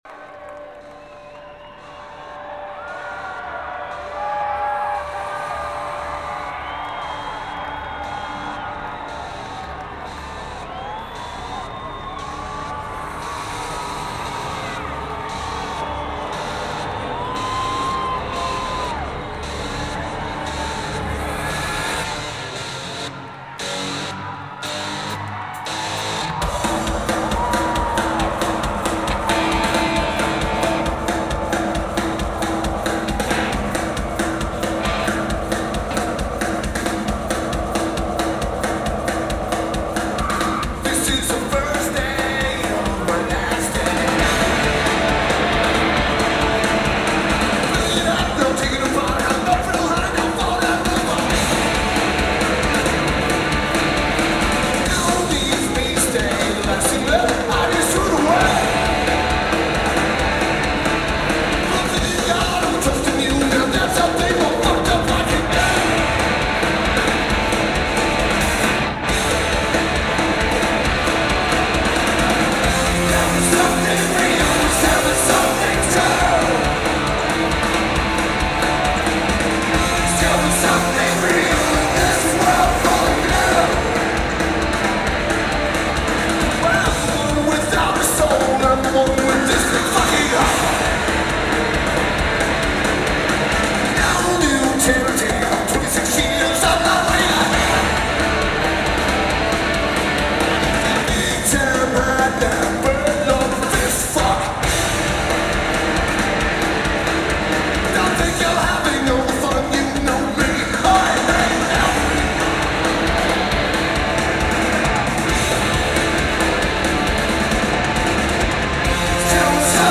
Schottenstein Center
Lineage: Audio - AUD (Sony ECM-DS70P + Sharp MD-SR60)